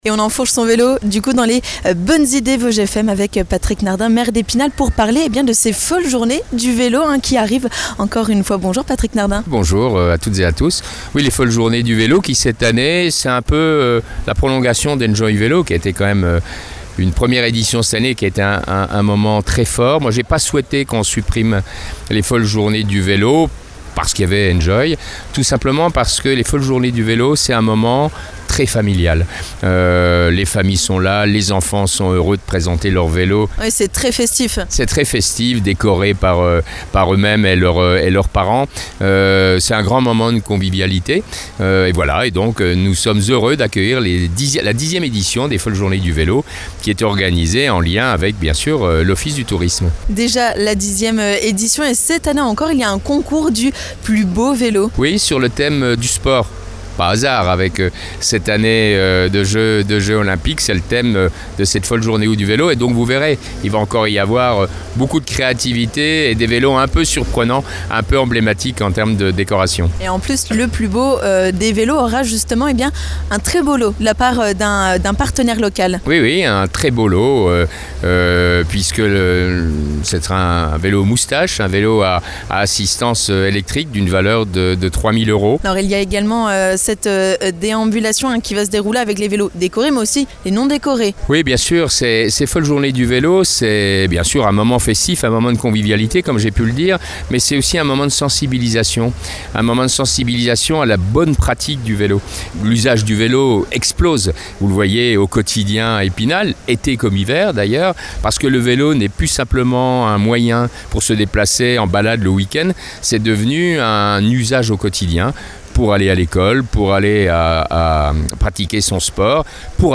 Pour en savoir plus, Patrick Nardin, maire d'Epinal, a répondu à nos questions dans les Bonnes Idées !